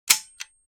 sniper_empty.wav